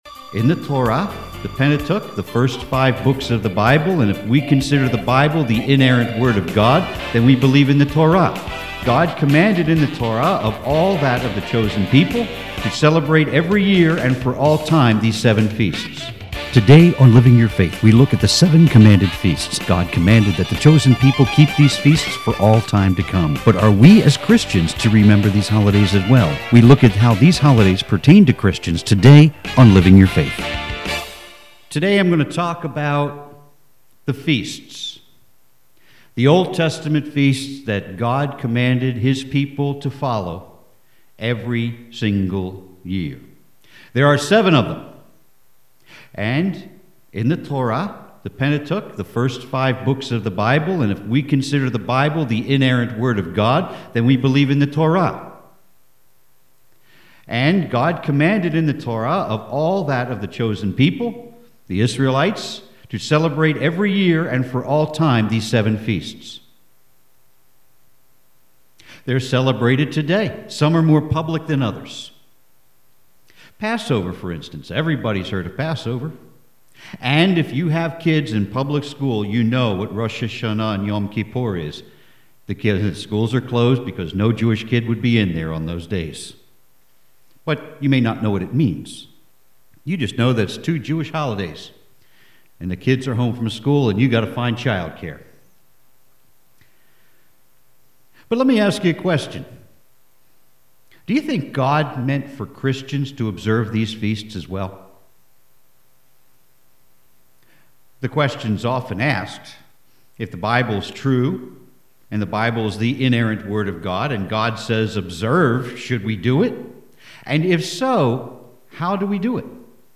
Here’s my sermon on the Seven Jewish Holidays – and why we who are saved and born again, should be celebrating them as well!n Click the link below Jewish Feasts For Christians